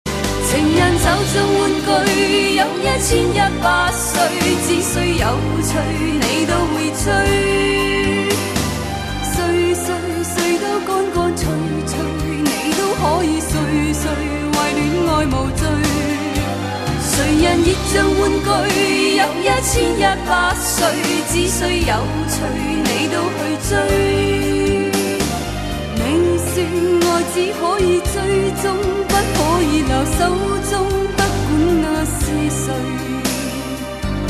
粤语